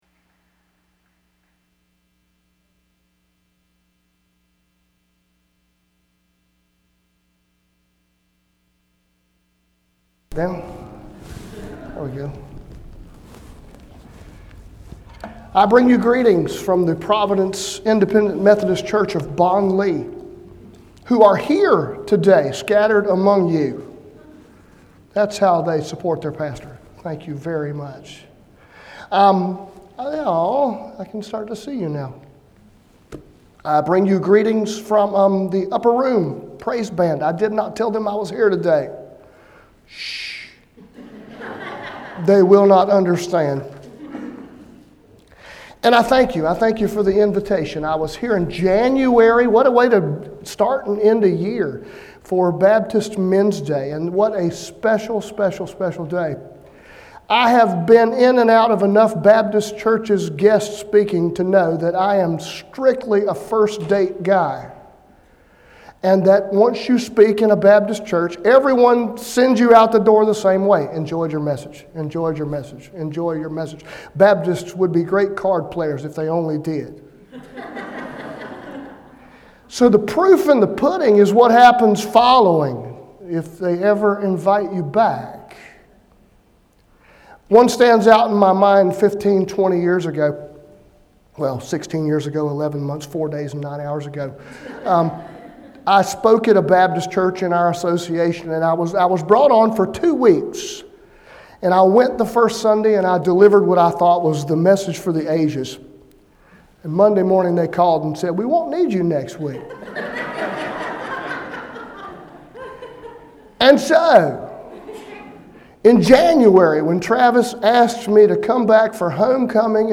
Tramway Baptist Church Sermons
Guest Speaker